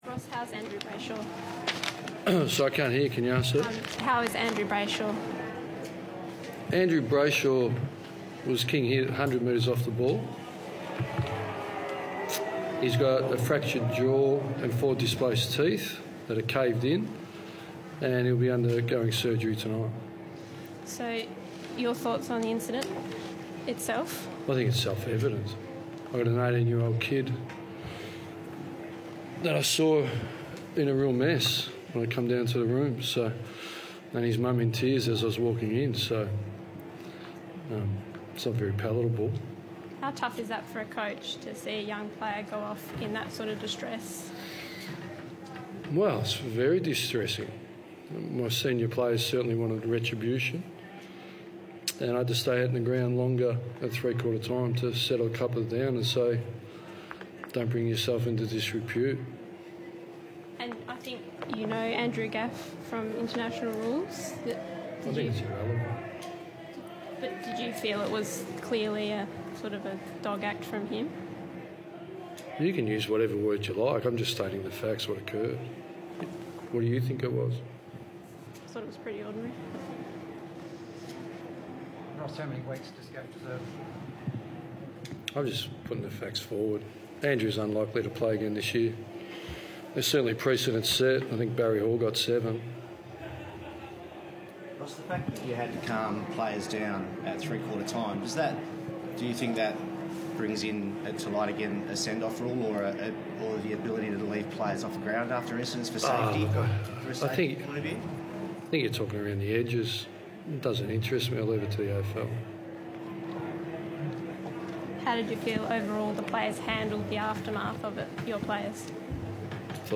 Ross Lyon post-match Q&A - Round 20 v WCE
Ross Lyon addresses the media following round 20 against the West Coast Eagles